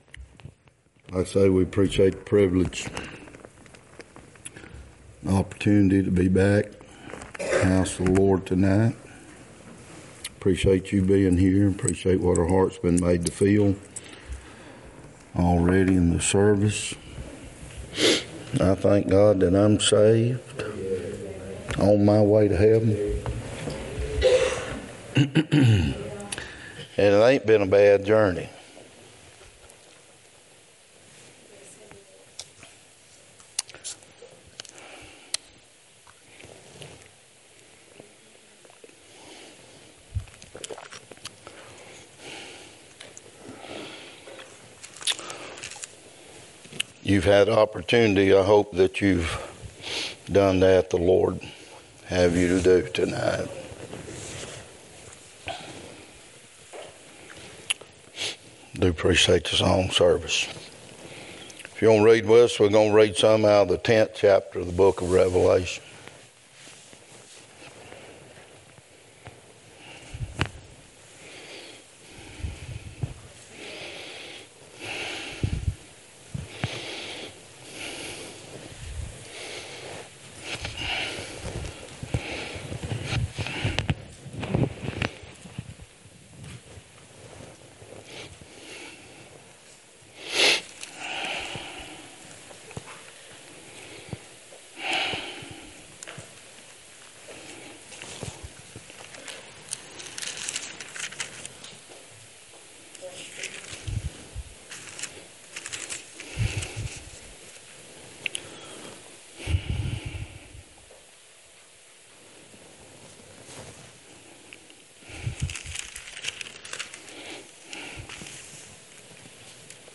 2025 Passage: Revelation 10:1-11 Service Type: Sunday Night Topics